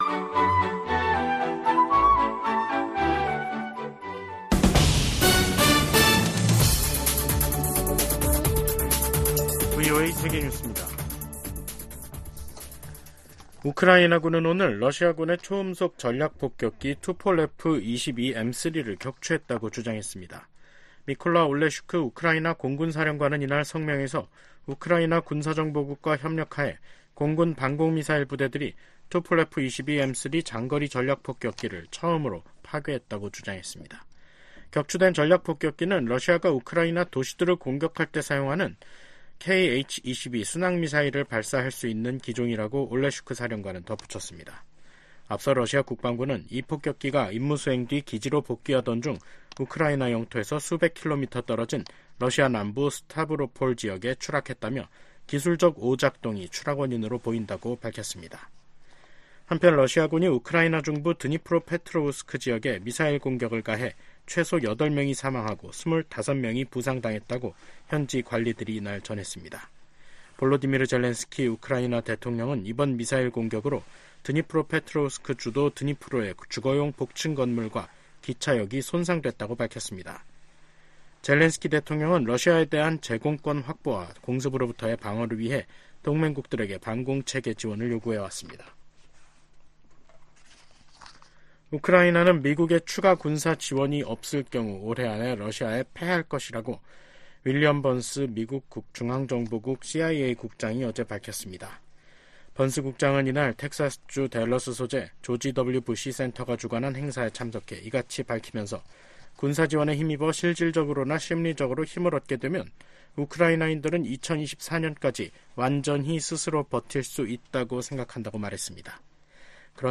VOA 한국어 간판 뉴스 프로그램 '뉴스 투데이', 2024년 4월 19일 3부 방송입니다. 일본을 방문 중인 미국 유엔대사가 유엔 총회나 외부 기관 활용 등 대북제재 패널 활동의 대안을 모색하고 있다고 밝혔습니다. 미국이 한국과 우주연합연습을 실시하는 방안을 추진 중이라고 미국 국방부가 밝혔습니다.